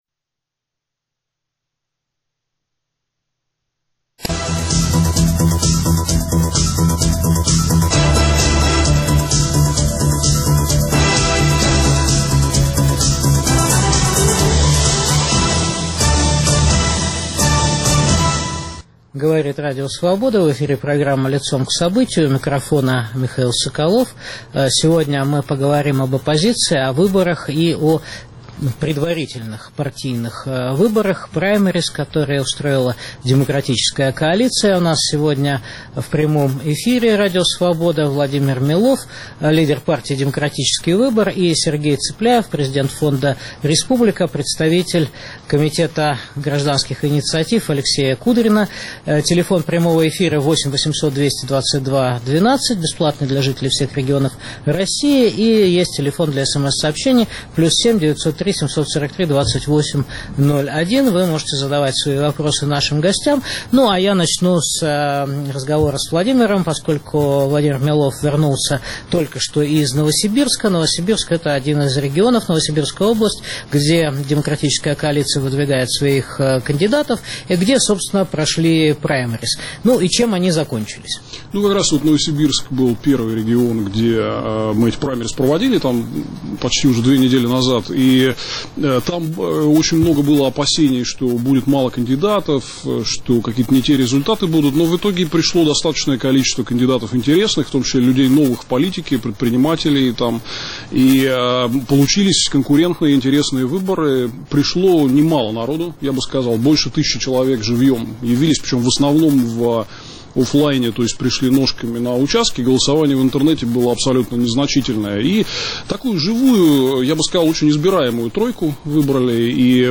Итоги праймериз "Демократической коалиции" подводят лидер партии "Демократический выбор" Владимир Милов и президент фонда "Республика" политолог Сергей Цыпляев (Петербург).